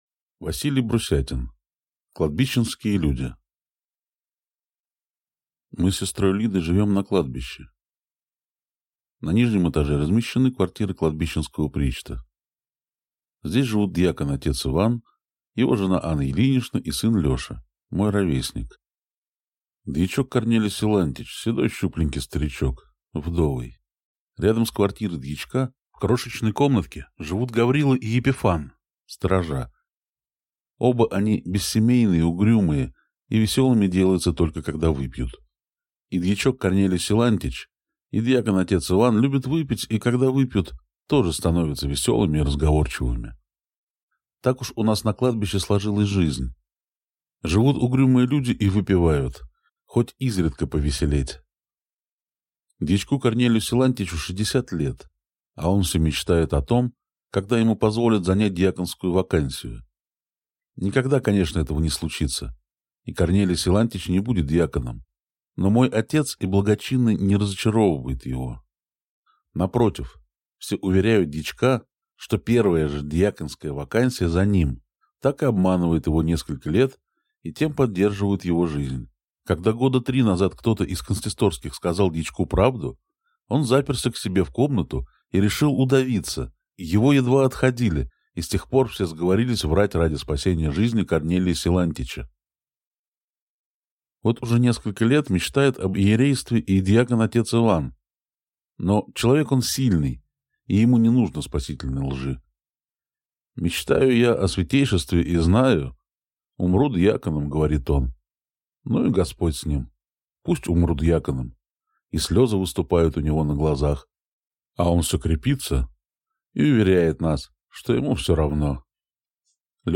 Аудиокнига Кладбищенские люди | Библиотека аудиокниг